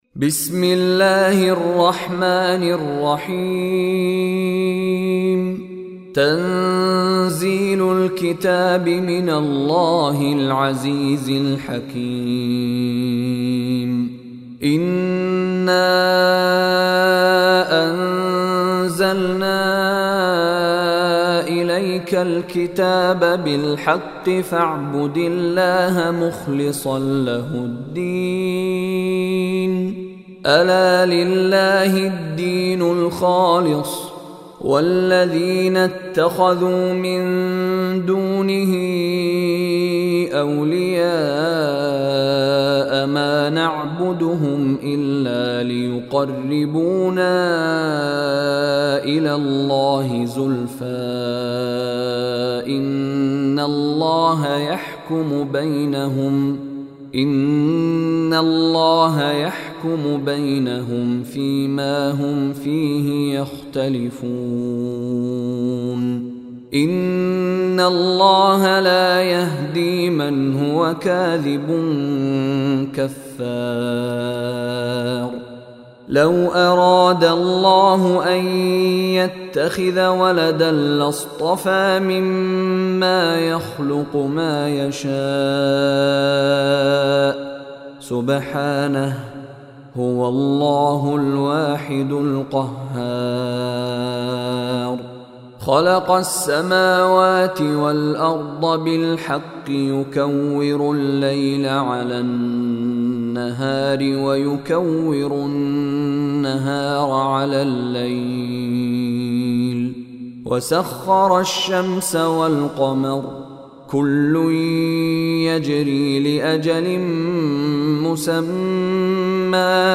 Surah Az-Zumar Recitation by Mishary Rashid
Surah Az-Zumar is 39 chapter of Holy Quran. Surah Az-Zumar listen online and download mp3 recitation / tilawat in the voice of Sheikh Mishary Rashid Alafasy.